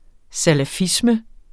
Udtale [ salaˈfismə ]